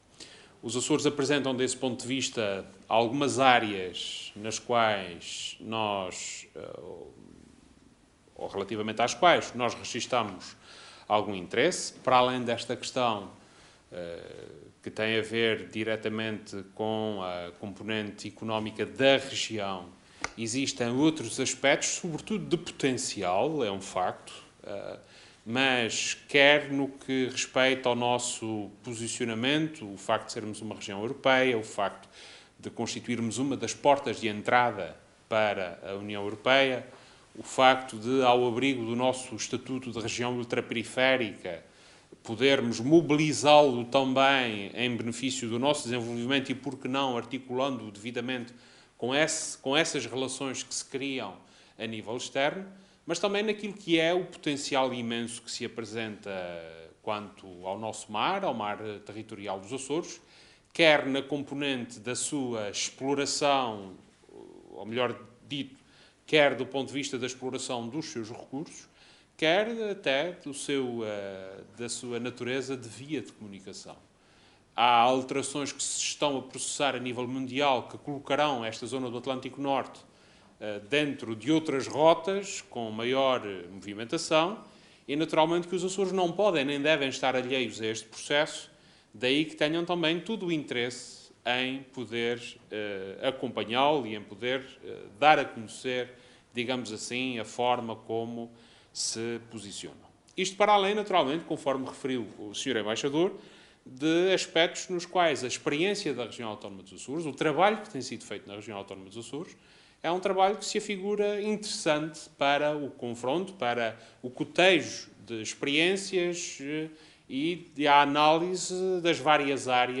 Vasco Cordeiro falava após ter recebido, em audiência, os representantes diplomáticos do Panamá, Paraguai, Colômbia, República Dominicana, México, Chile, Argentina, Cuba, Equador e Brasil, que iniciaram hoje uma visita de quatro dias aos Açores, promovida pelo Instituto para a Promoção e Desenvolvimento da América Latina.